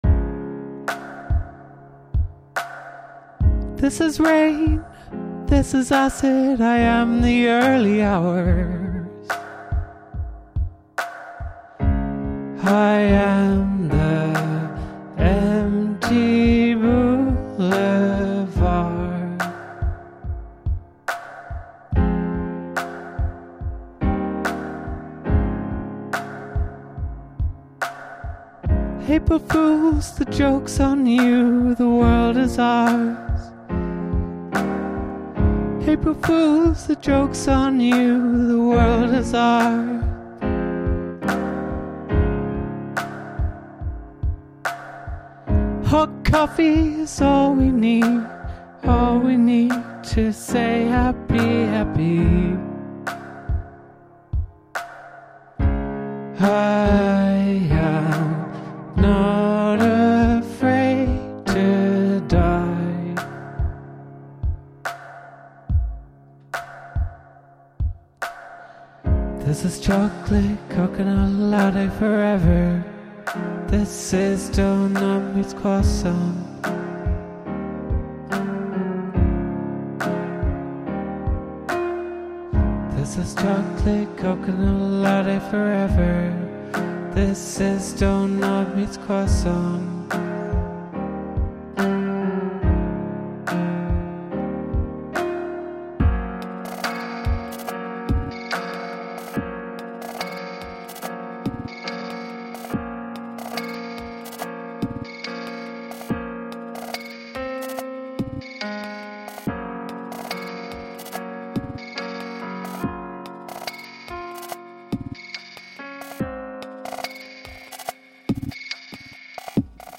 BELOW that, you’ll find a demo of the song Hot Coffee, very much in a first draft.